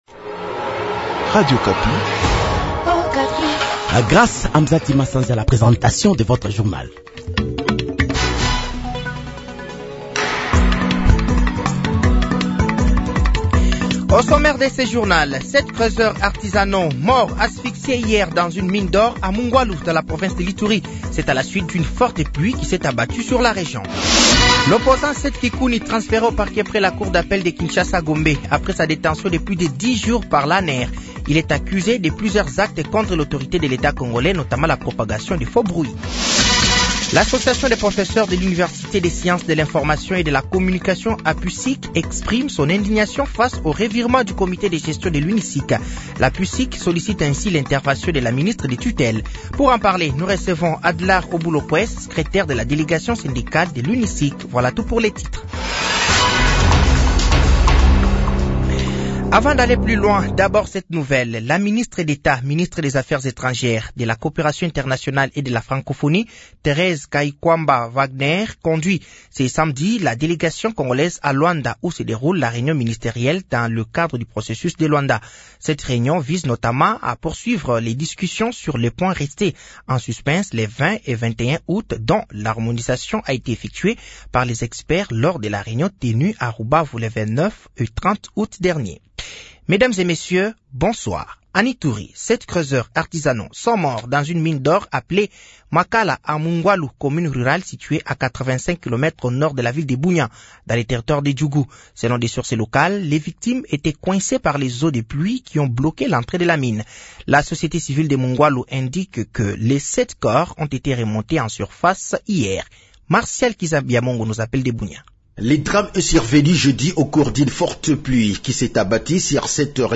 Journal français de 18h de ce samedi 14 septembre 2024